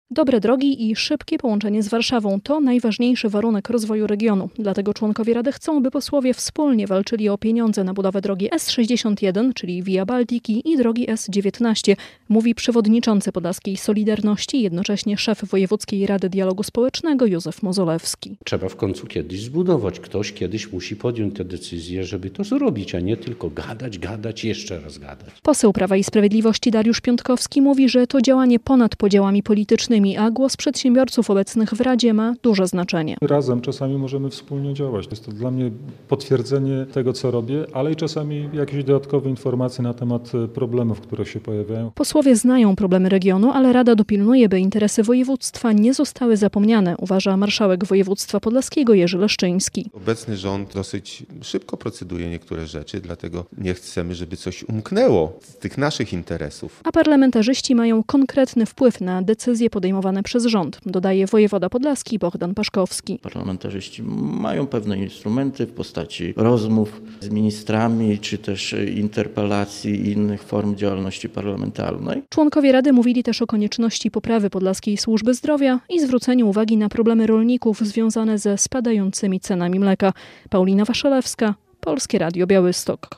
Wojewódzka Rada Dialogu Społecznego rozmawiała o najważniejszych potrzebach Podlaskiego - relacja